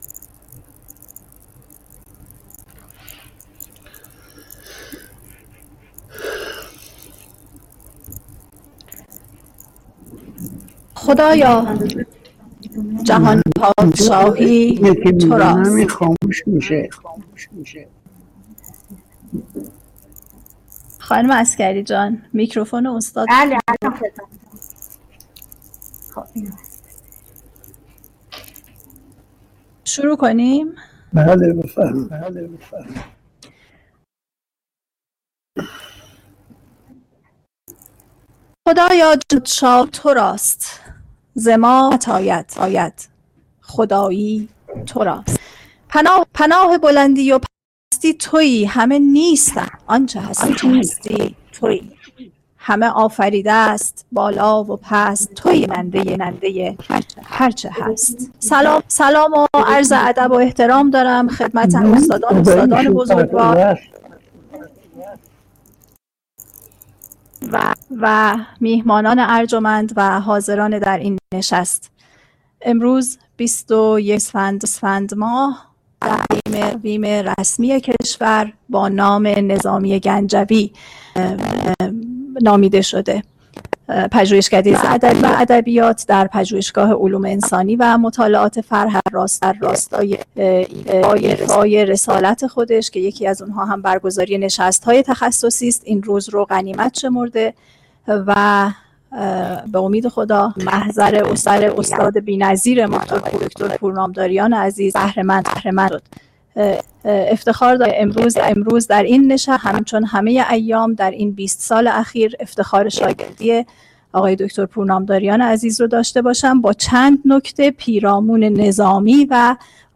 سخنران: تقی پورنامداریان عنوان سخنرانی: چند نکته پیرامون نظامی و آثارش دبیر نشست